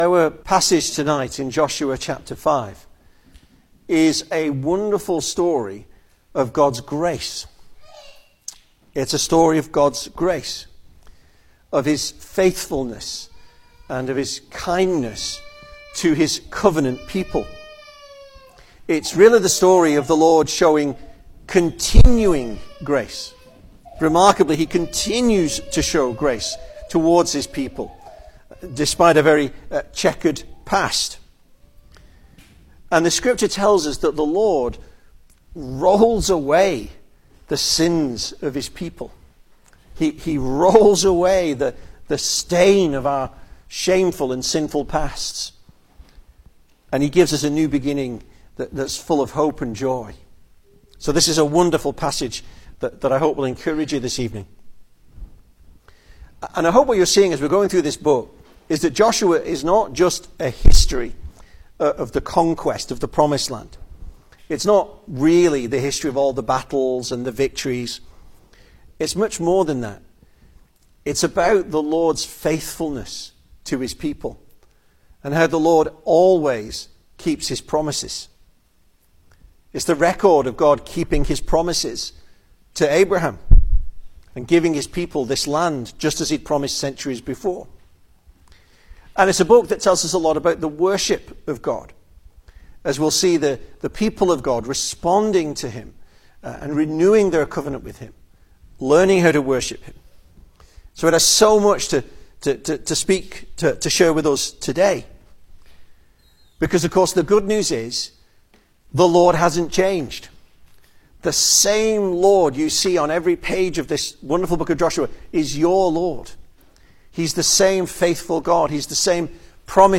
2025 Service Type: Sunday Evening Speaker